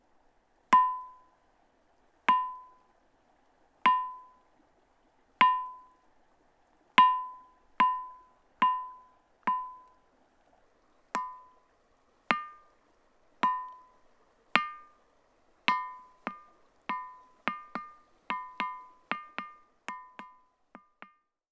Type: Klangstein
Formen er avlang, 145 x 85 cm, og tykkelsen er fra 25 (mot øst) til 40 cm (mot vest). Den har tydelig klang, og gir to lyse tonehøyder (H og D), særlig på enden nærmest gangbrua, mot øst – jf lydopptaket.
Hør lyden fra Osevad